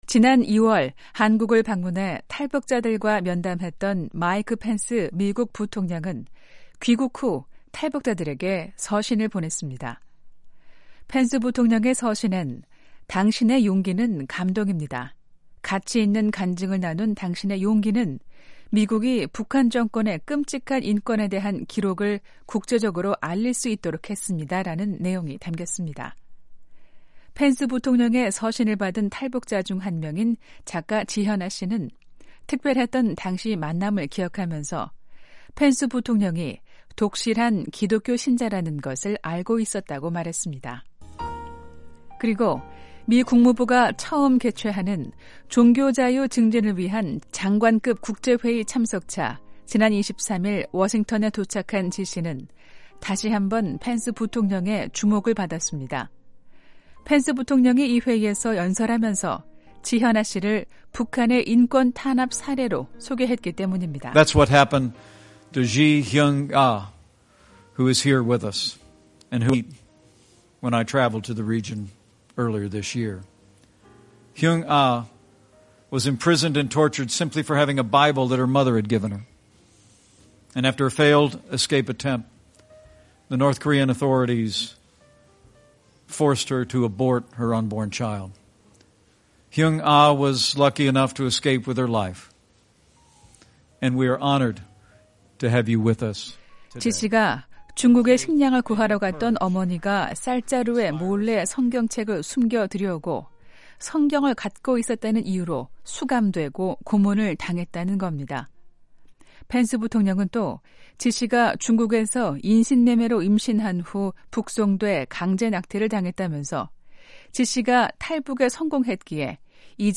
생생 라디오 매거진, 한 주 간 북한 관련 화제성 뉴스를 전해 드리는 ‘뉴스 풍경’ 입니다.